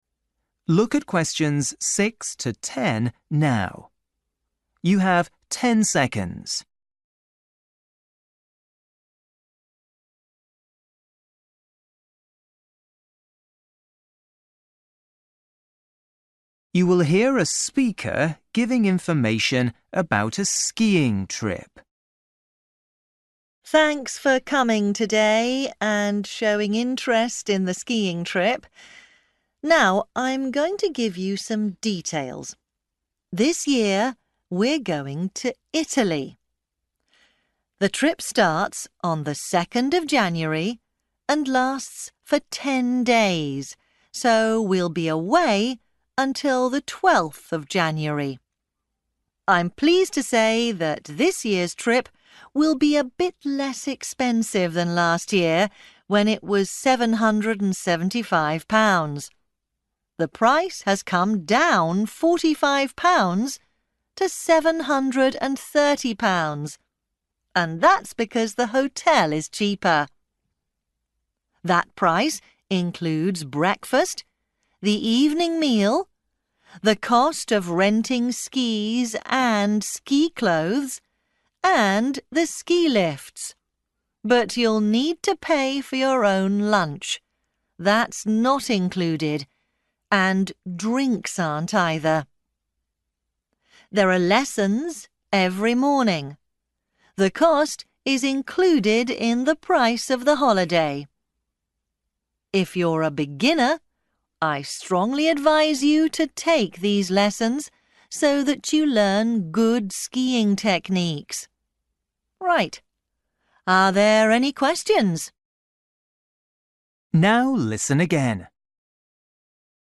You will hear a speaker giving information about a skiing trip.